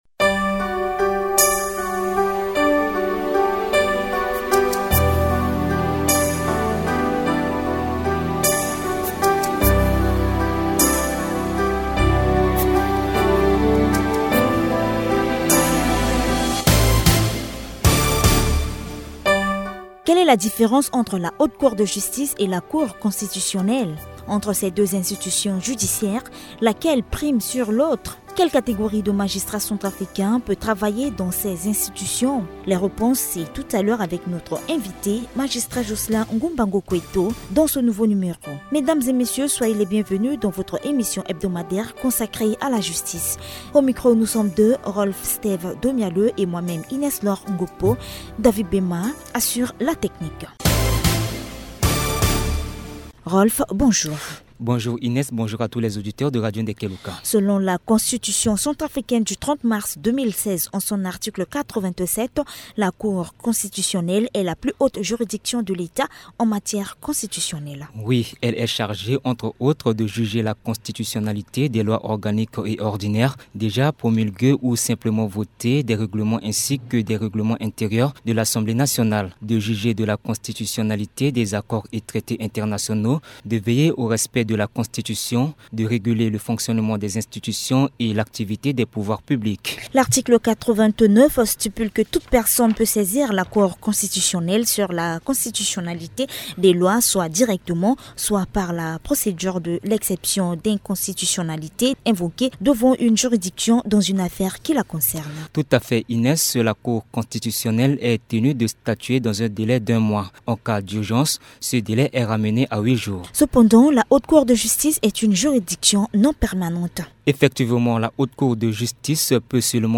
Grace à un partenariat stratégique avec le ministère de l’éducation nationale et la fondation Hirondelle, l’institution met en oeuvre depuis début février un programme d’enseignement à la radio dénommé « pret à apprendre ». Diffusé par radio Ndèkè Luka et certaines radios communautaires, les biens fondés de ce projet sont évoqués dans cette table ronde avec les responsables du projet et les cadres du ministère de l’éducation nationale.